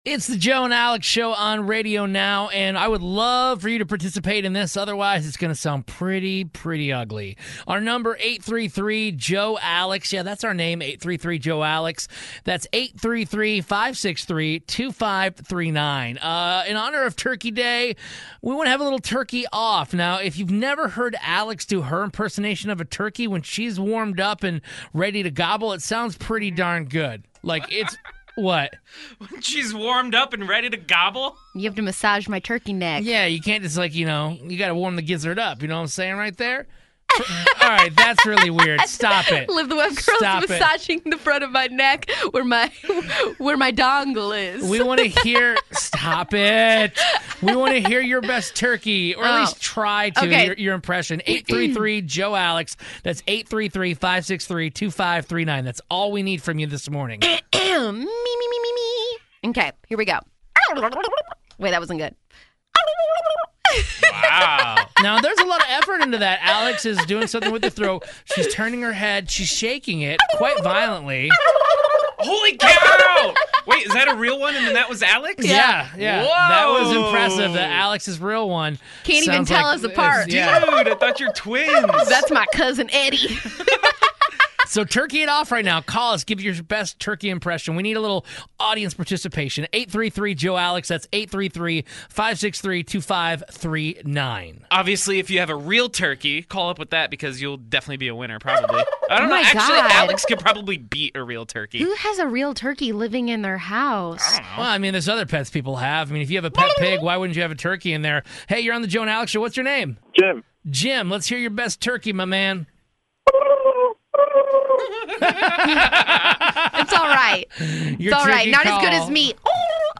Best Turkey Gobble Impressions
Who do you think on the show can sound the MOST like a real turkey?!